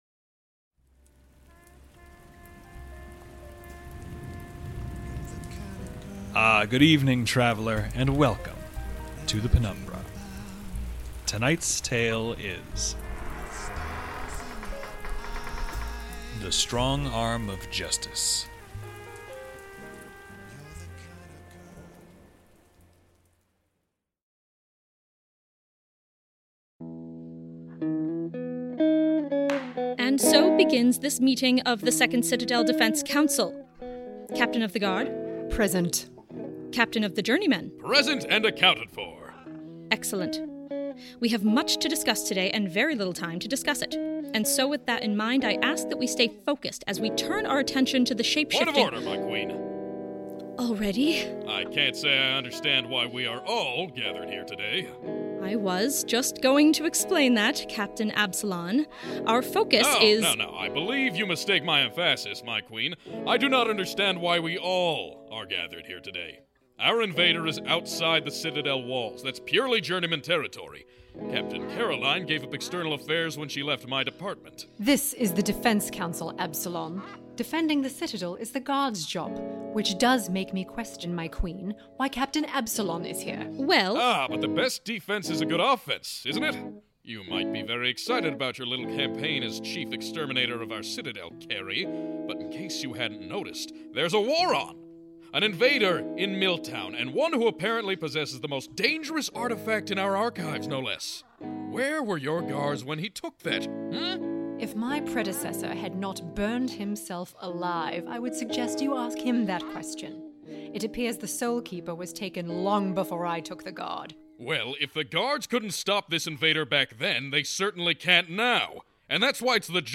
Fiction, Thirst, Second Citadel, Juno Steel, Scifi, Horror, Audio Drama, Rusty Quill, Audio Fiction, The Penumbra Podcast, Penumbra, Thirst Podcast, Performing Arts, Arts, Comedy, Science Fiction